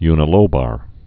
(ynə-lōbər, -bär)